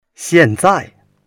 xian4zai4.mp3